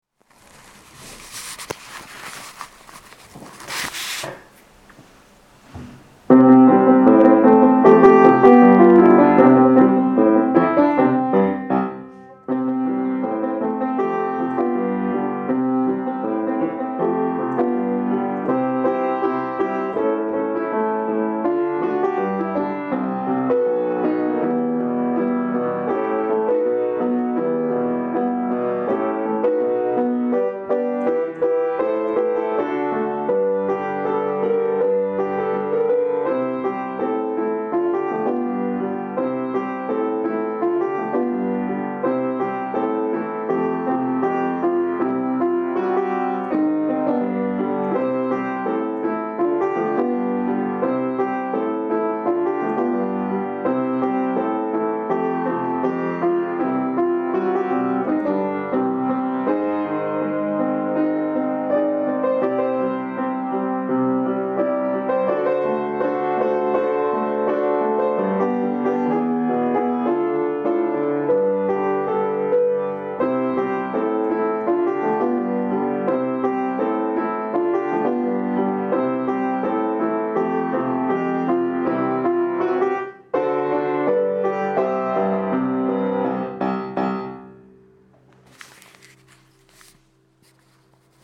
doprovod